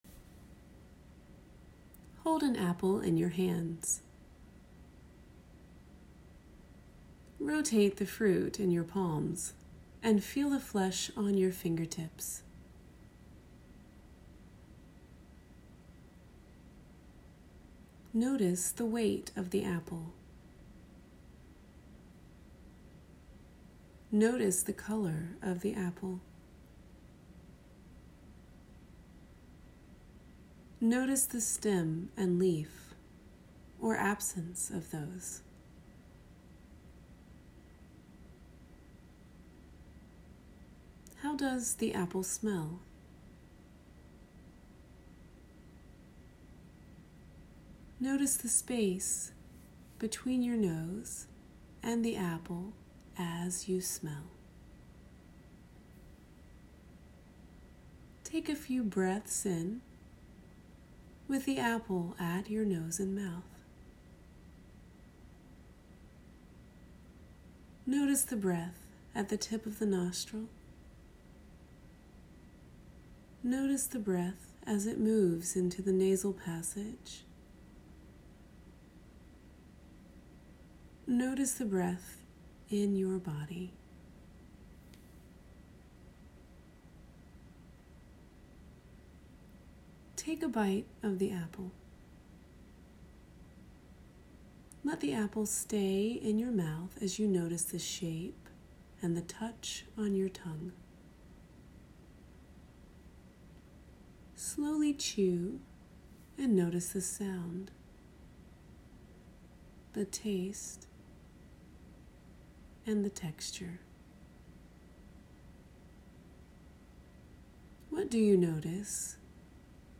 Guided Apple Script
apple-exercise.m4a